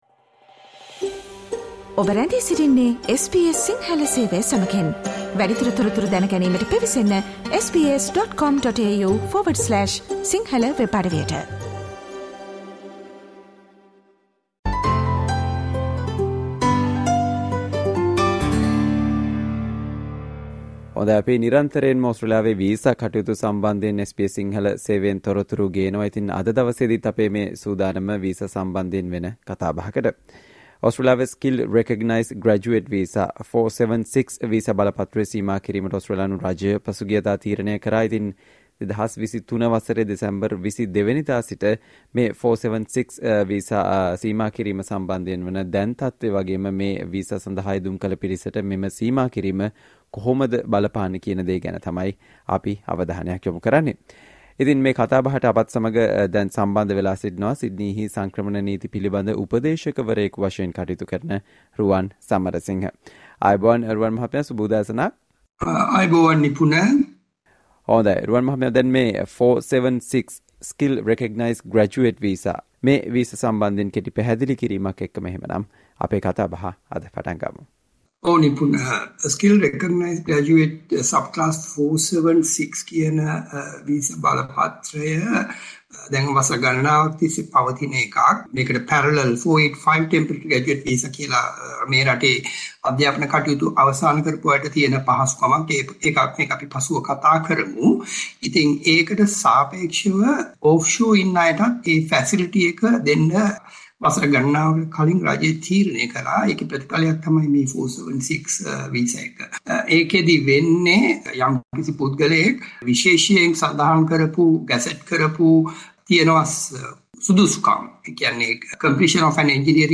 SBS Sinhala discussion on Important information about the government's visa cap on 476 visas due to Australia's new migration strategy